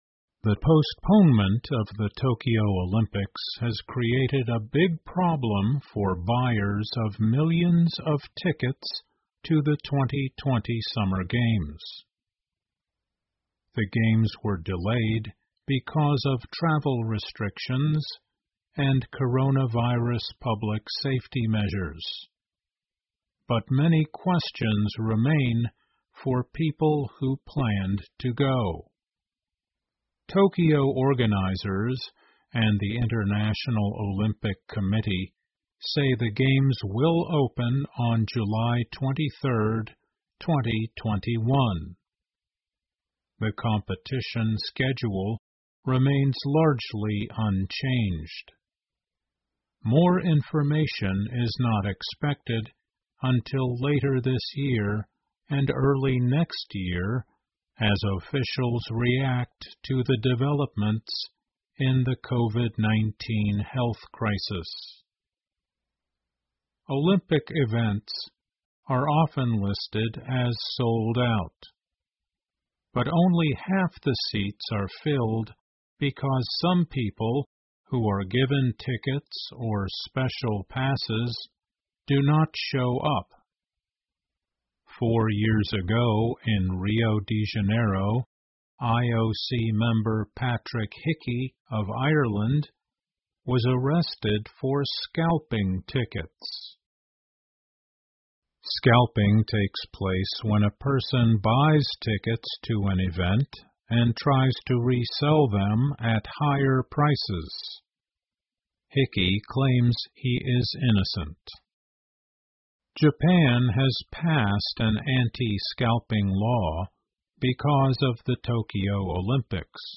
VOA慢速英语--东京奥运会的推迟给购票者带来了麻烦 听力文件下载—在线英语听力室